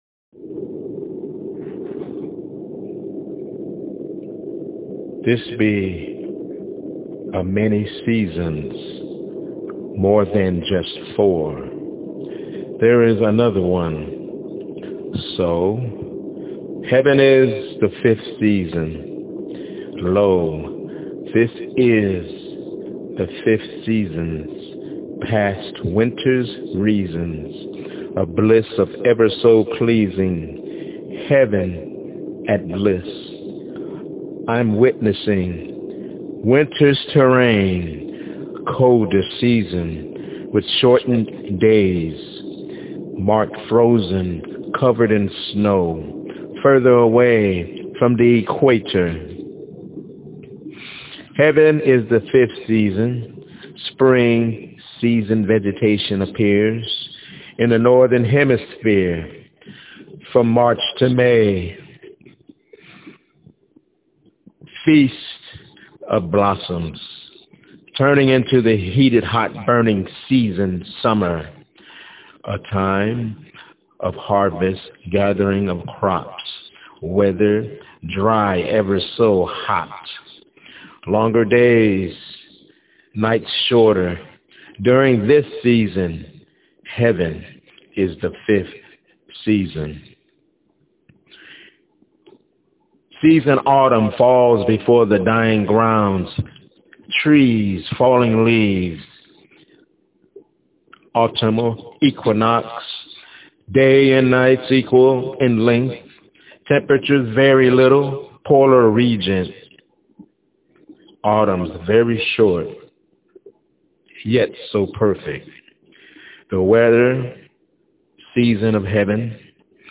Heaven Is the Fifth Season- My Spokenword-